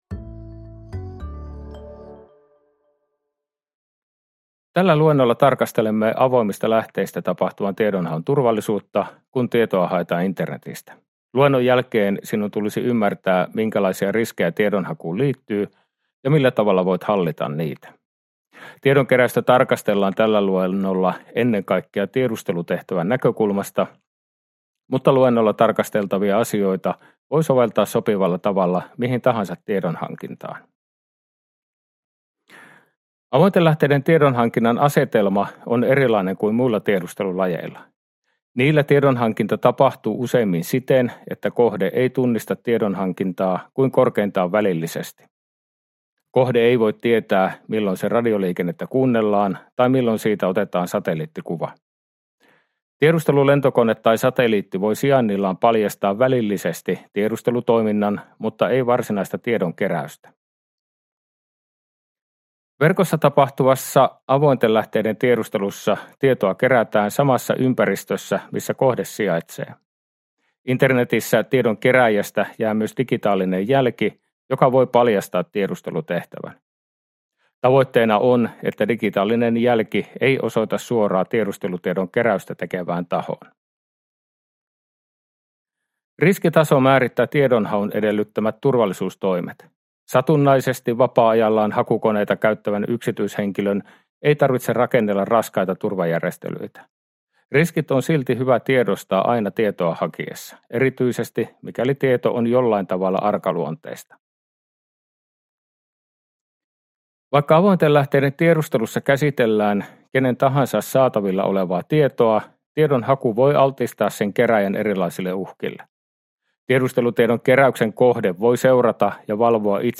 Päivitetty versio luennosta lv 25-26 toteutukseen.